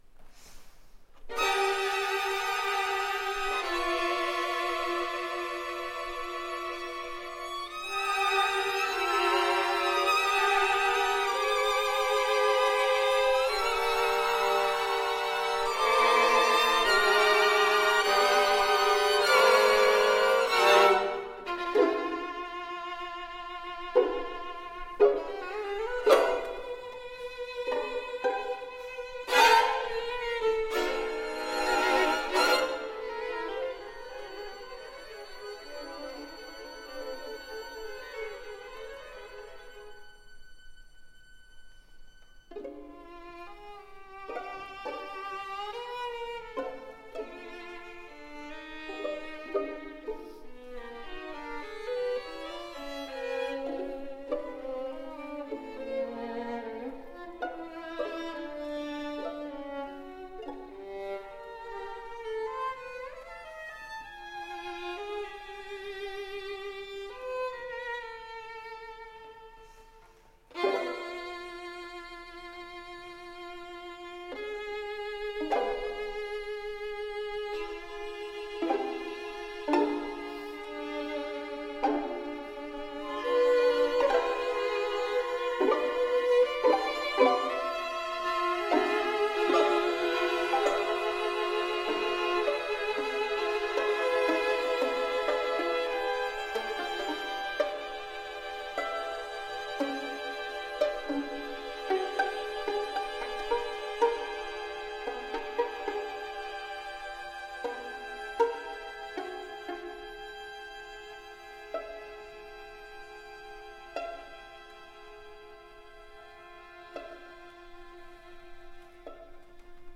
Artist Faculty Concert recordings - July 2, 2014 | Green Mountain Chamber Music Festival
violin